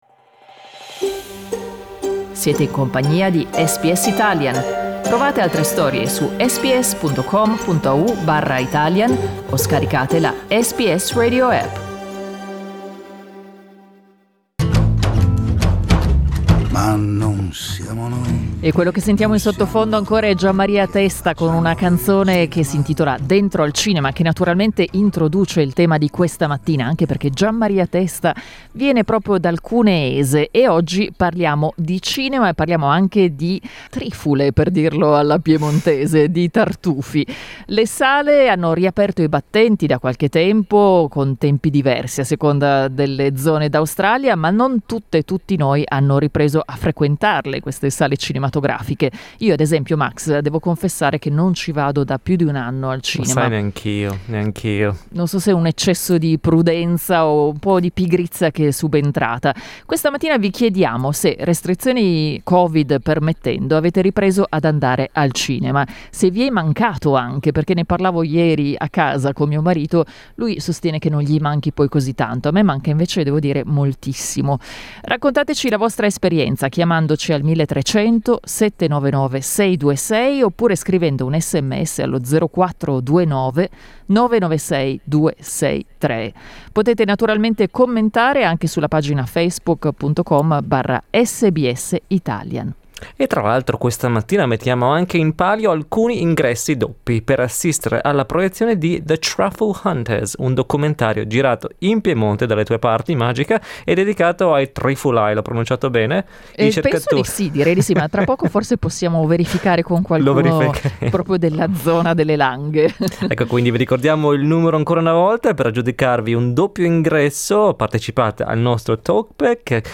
Questa mattina abbiamo chiesto ad ascoltatori e ascoltatrici se, restrizioni COVID permettendo, abbiano ripreso ad andare al cinema.